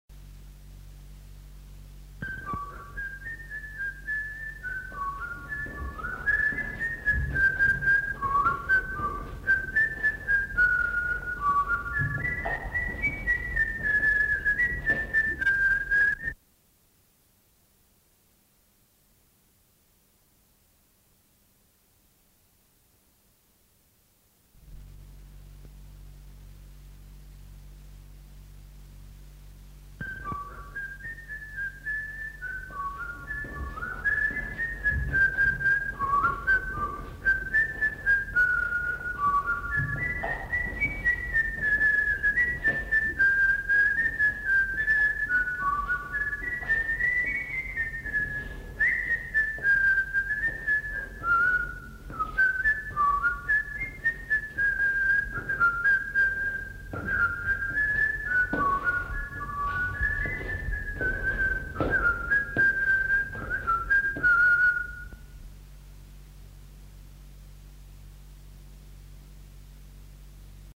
Aire culturelle : Haut-Agenais
Genre : chant
Effectif : 1
Type de voix : voix d'homme
Production du son : sifflé
Danse : valse
Coupure puis reprise.